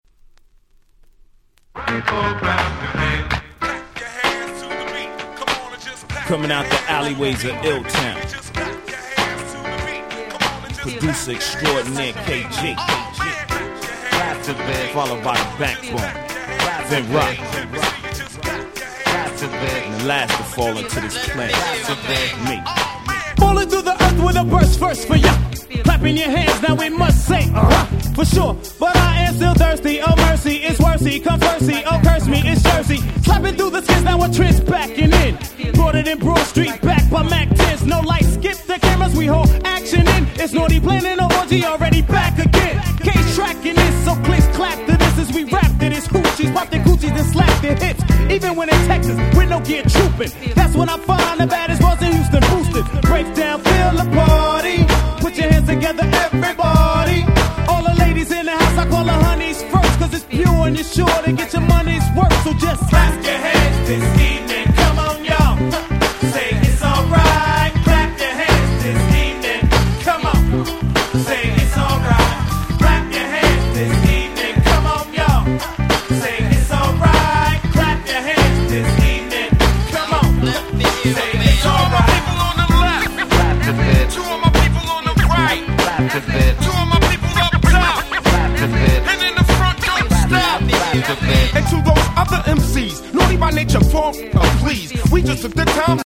95' Smash Hit Hip Hop !!
フックも分かりやすくて非常にフロア映えしますね！！